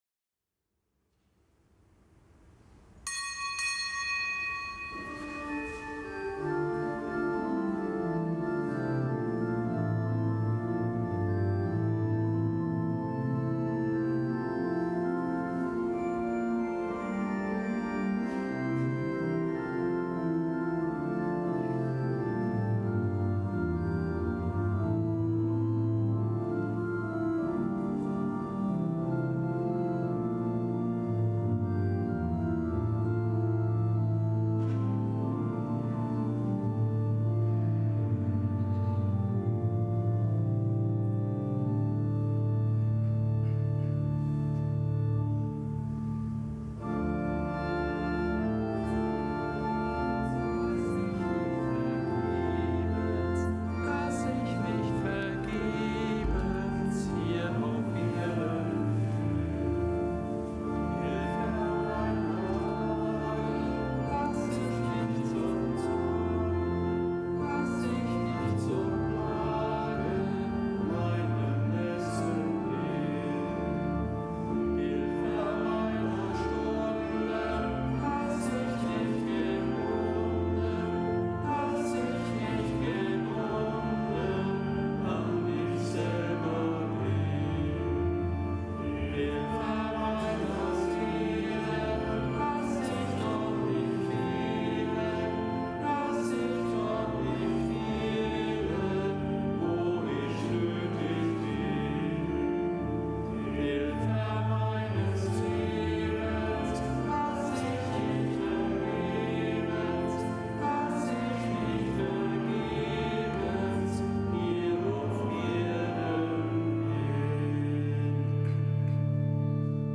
Kapitelsmesse am Gedenktag des Heilligen Josaphat
Kapitelsmesse aus dem Kölner Dom am Gedenktag des Heilligen Josaphat, einem Bischof von Polozk in Weißrussland, der als Märtyrer starb und der in der Weltkirche am 12. November.